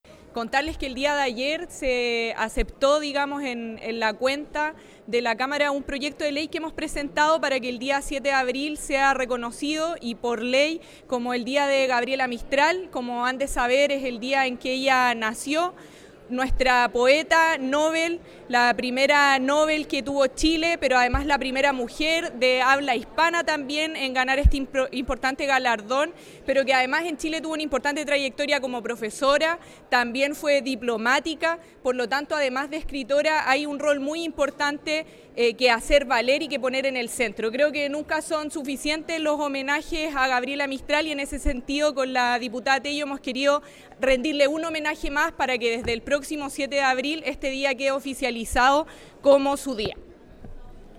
La diputada Rojas señaló que